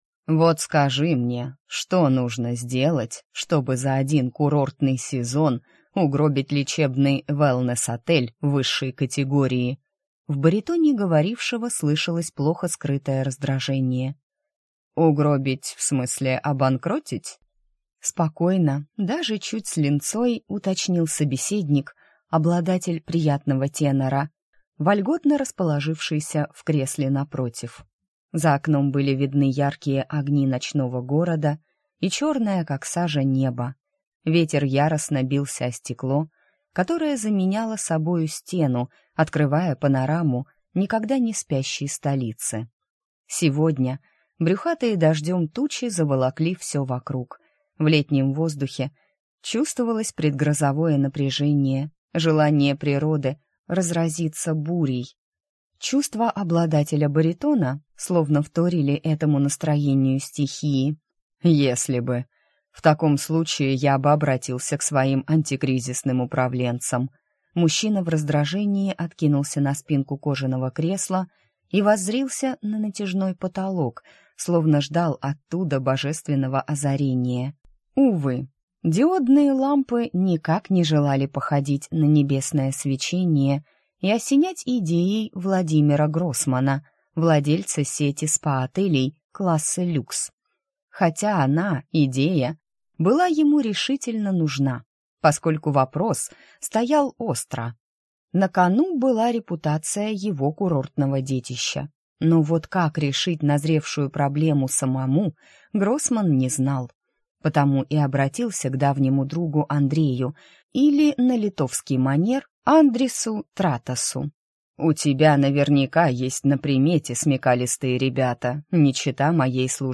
Аудиокнига Курортный обман. Рай и гад | Библиотека аудиокниг